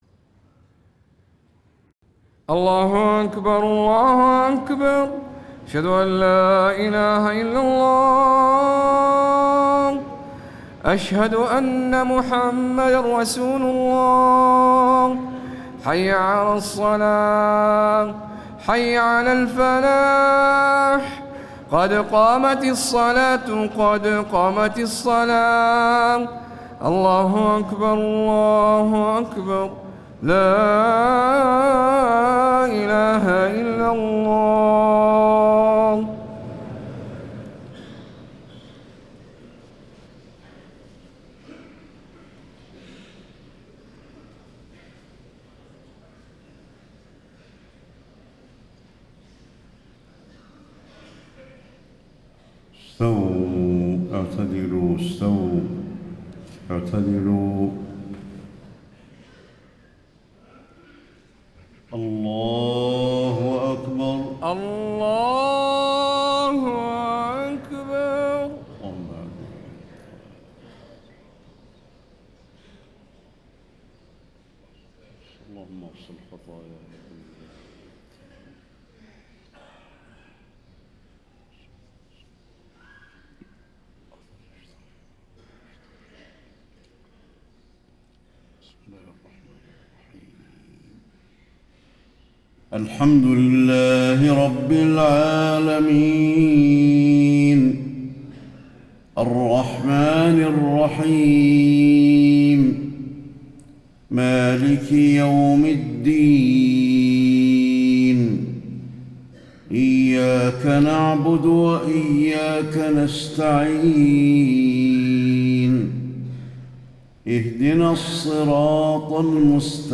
صلاة الفجر 3-4-1435هـ من سورة الزخرف > 1435 🕌 > الفروض - تلاوات الحرمين